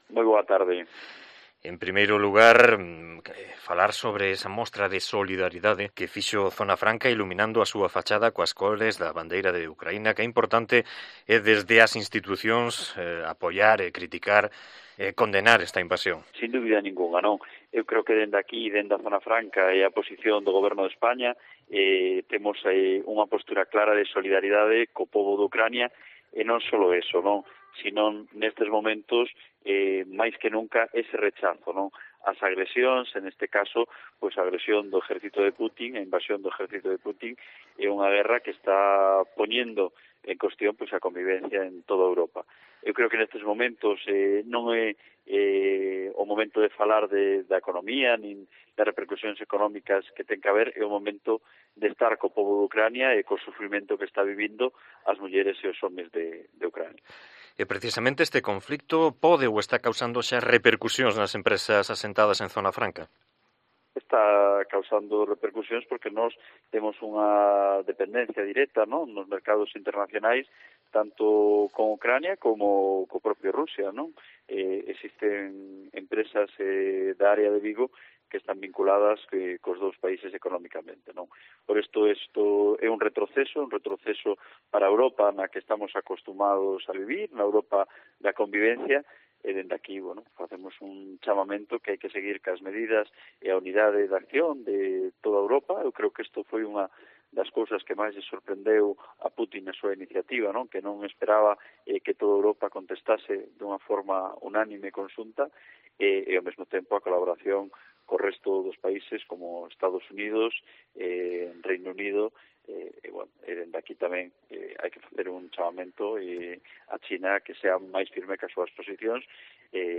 Entrevista a David Regades, delegado de Zona Franca de Vigo